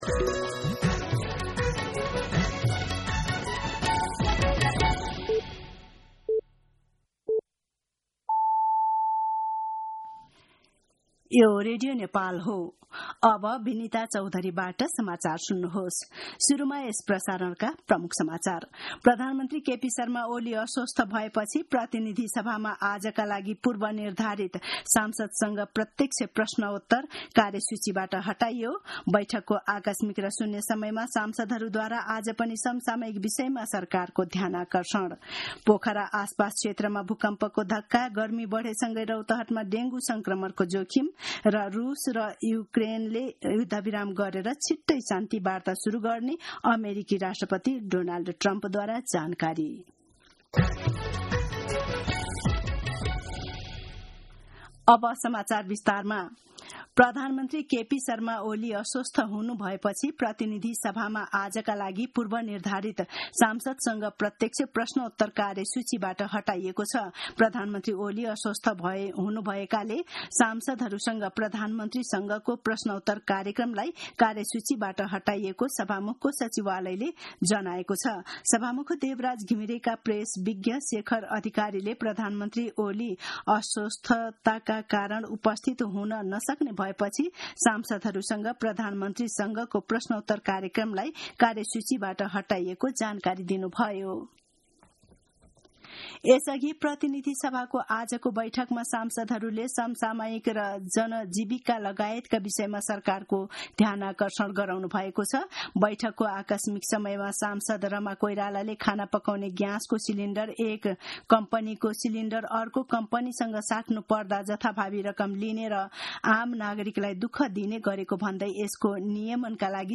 दिउँसो ३ बजेको नेपाली समाचार : ६ जेठ , २०८२